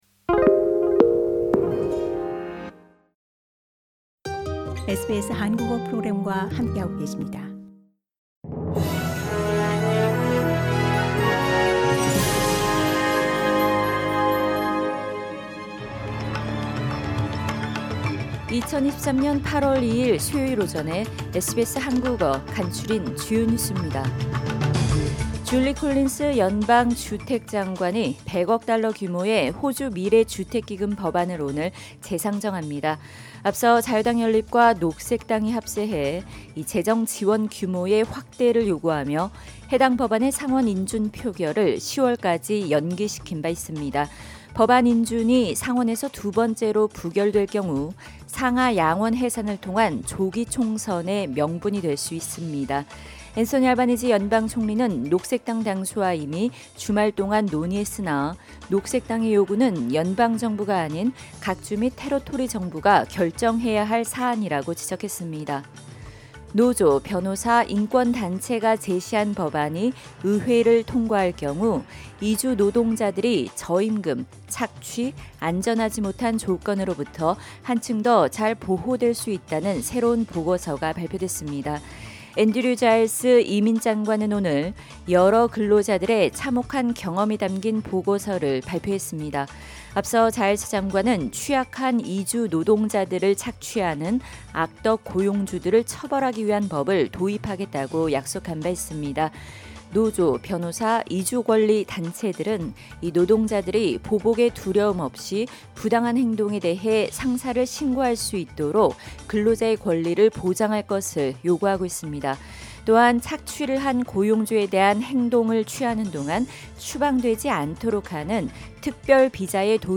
SBS 한국어 아침 뉴스: 2023년 8월2일 수요일